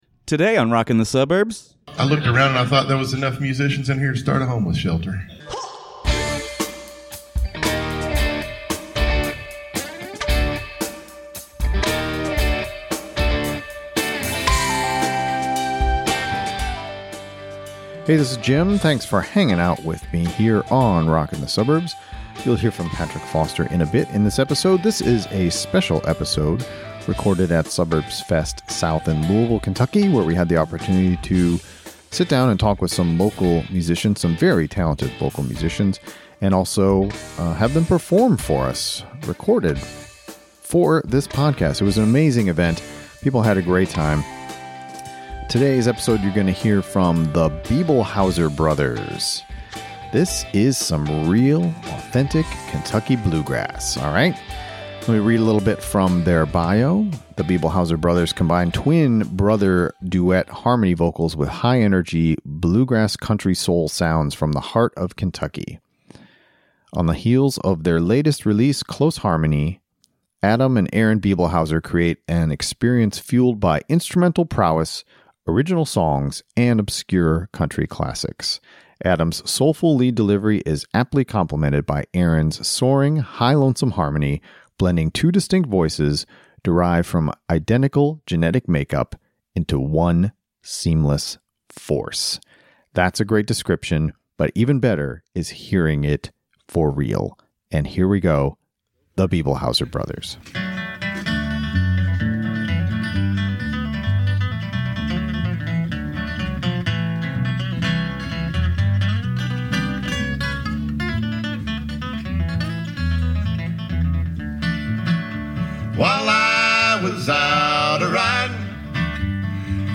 modern bluegrass stylings